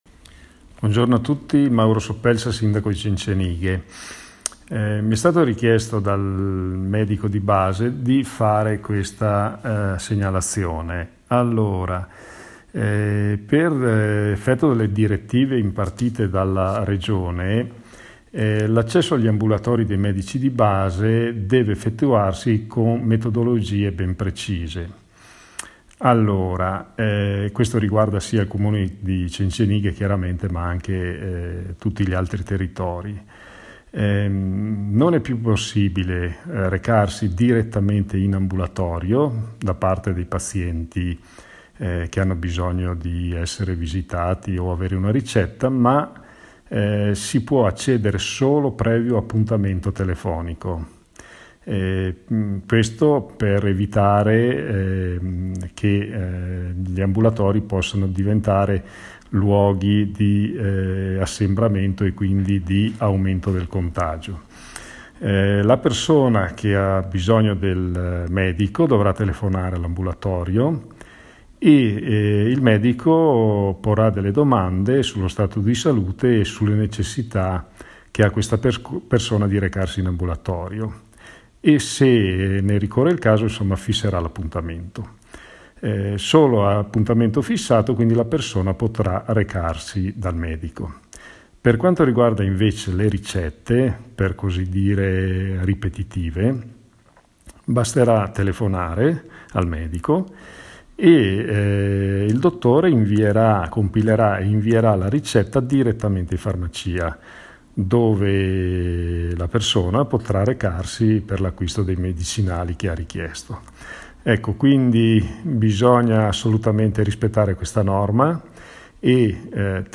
COMUNICAZIONE DEL SINDACO MAURO SOPPELSA.